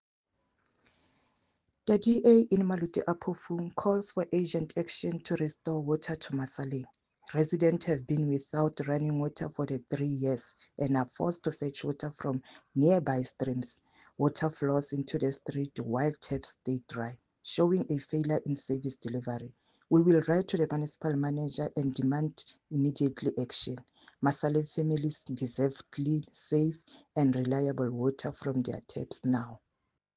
English and Sesotho soundbites by Cllr Ana Motaung and